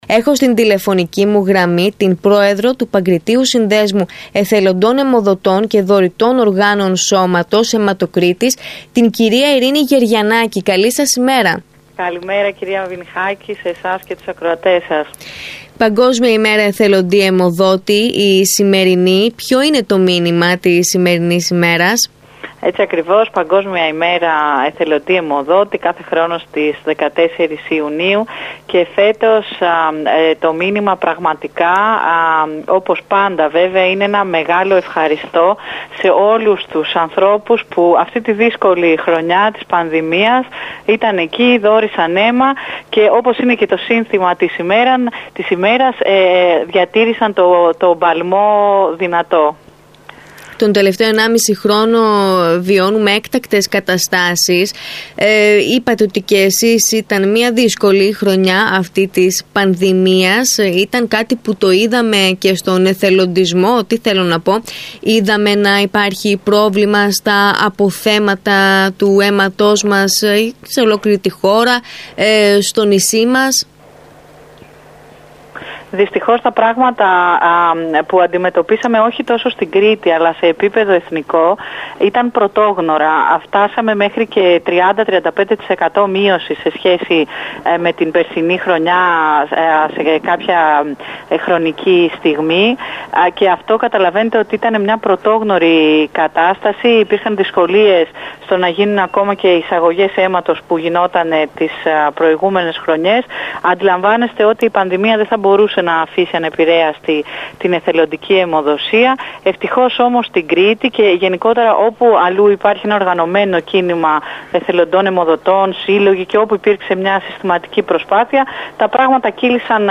δήλωσε στον ΣΚΑΪ 92,1 Κρήτης